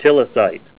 Help on Name Pronunciation: Name Pronunciation: Tilasite